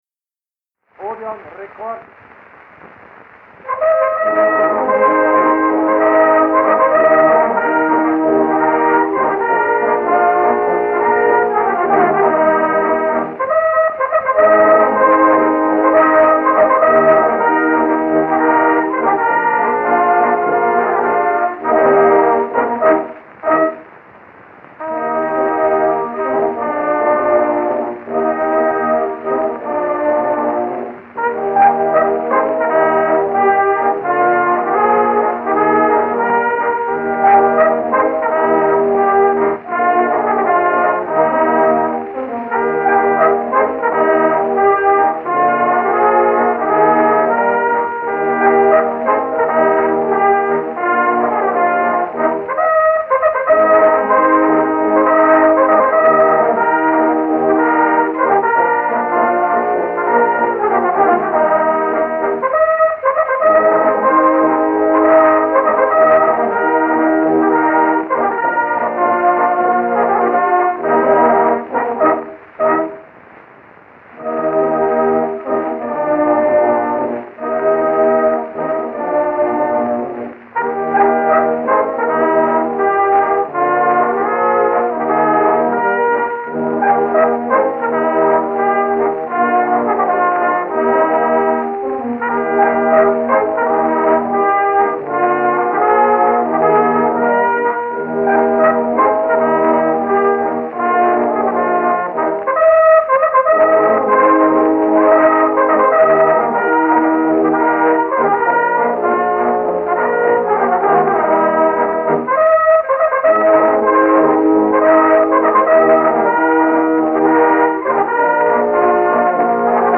Wien (Vienna) c. August 1906